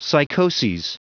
Prononciation du mot psychoses en anglais (fichier audio)
Prononciation du mot : psychoses